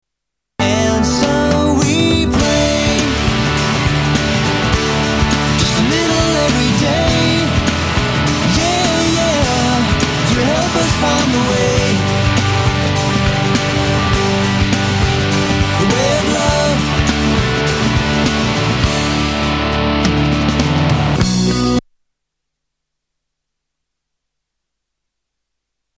STYLE: Pop
melodic power pop